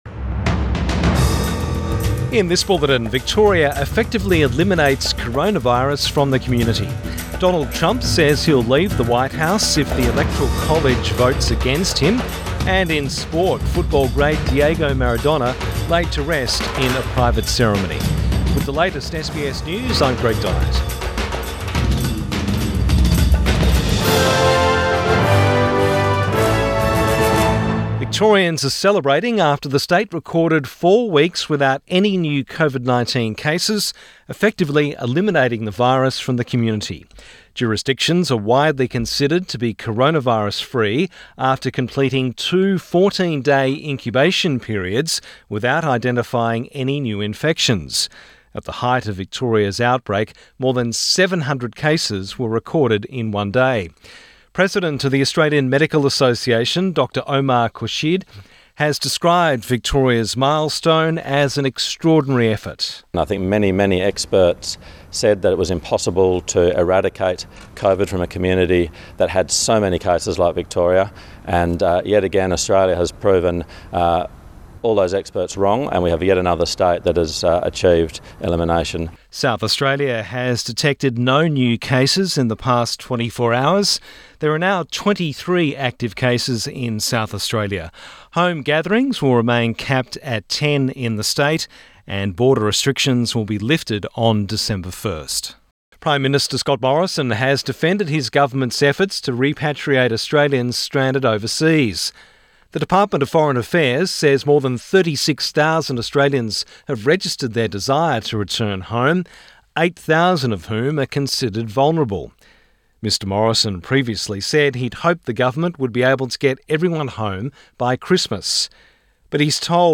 Midday bulletin 27 November 2020